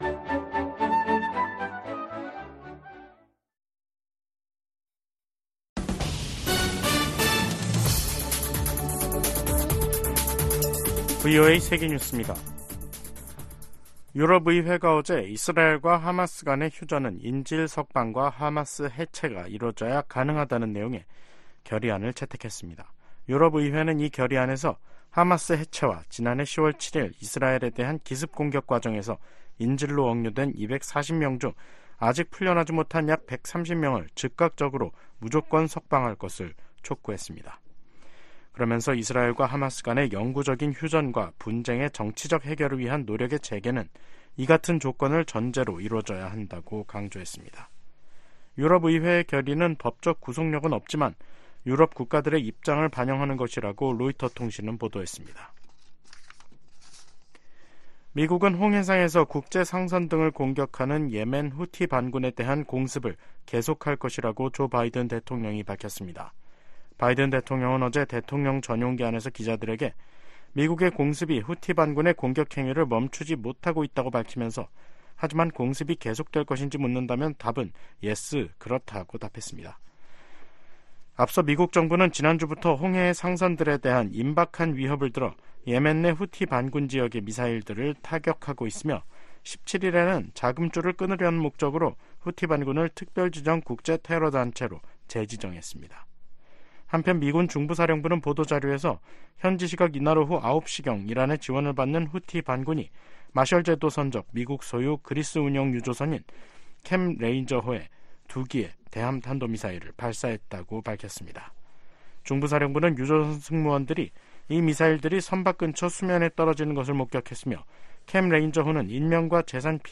VOA 한국어 간판 뉴스 프로그램 '뉴스 투데이', 2024년 1월 19일 3부 방송입니다. 북한이 수중 핵무기 체계 '해일-5-23'의 중요 시험을 동해 수역에서 진행했다고 발표했습니다. 유엔 안전보장이사회가 새해 들어 처음 북한 관련 비공개 회의를 개최한 가운데 미국은 적극적인 대응을 촉구했습니다. 최근 심화되는 북러 군사협력으로 향후 10년간 북한의 역내 위협 성격이 급격하게 바뀔 수 있다고 백악관 고위 당국자가 전망했습니다.